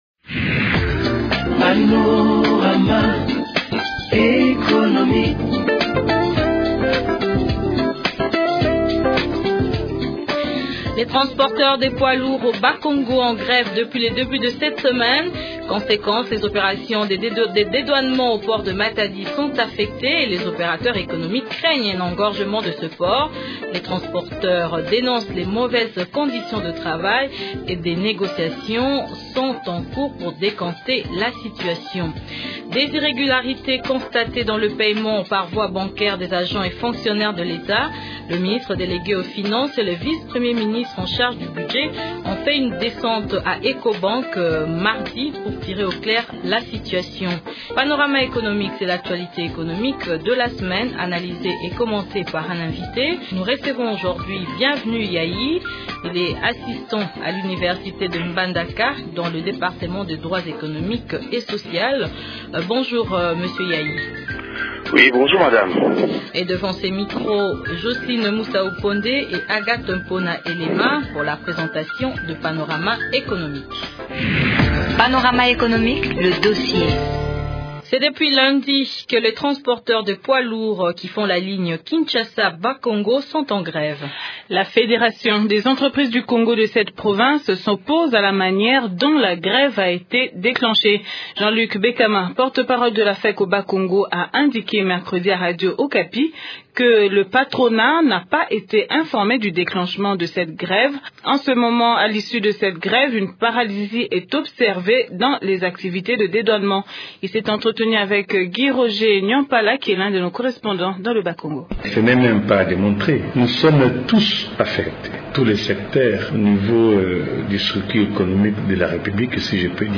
Ce sont notamment les sujets commentés et analysés au cours du magazine économique